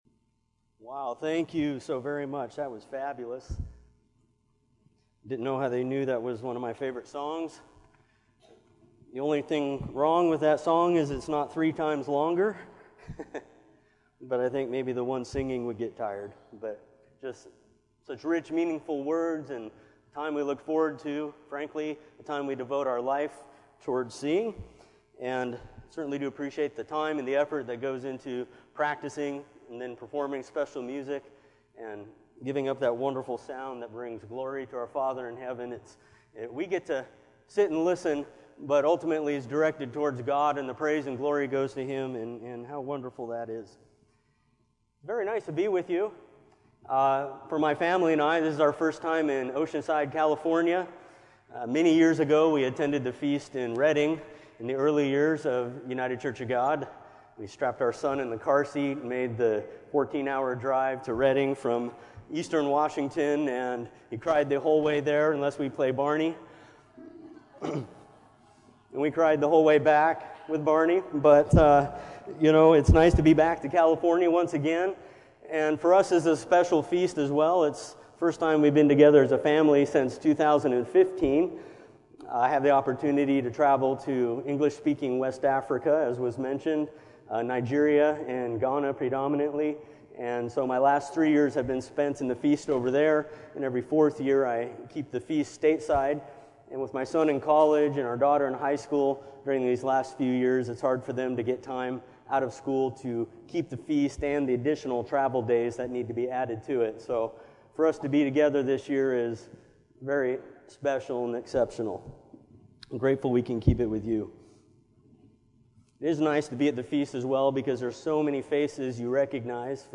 This sermon was given at the Oceanside, California 2019 Feast site.